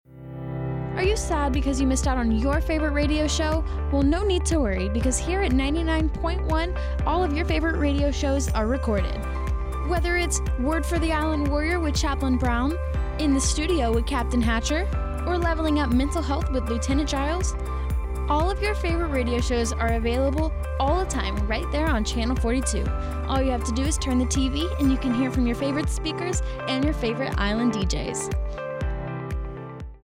NSFDiegoGarciaRadioSpotChannel 42